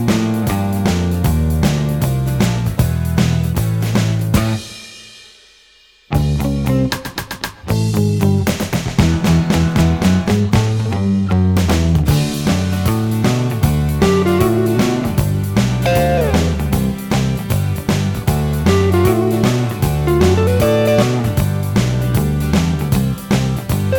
Minus Lead Guitar Rock 2:41 Buy £1.50